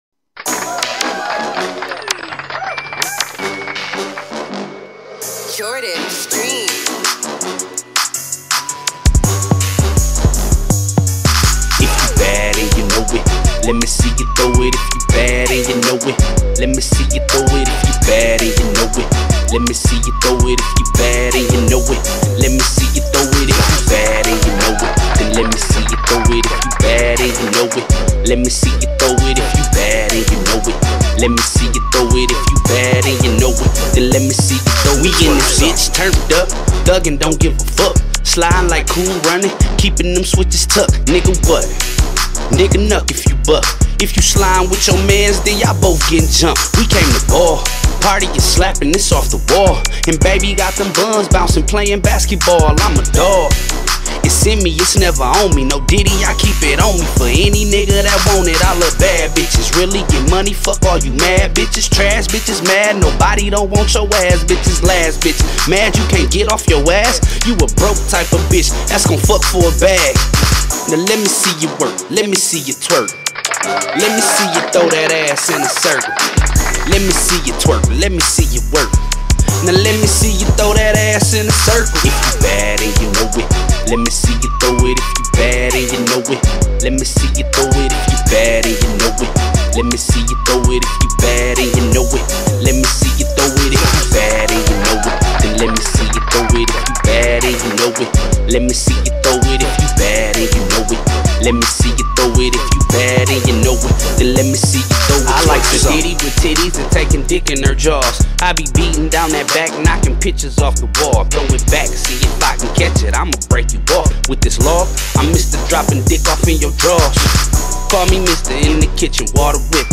Зараженная ритмом и позитивом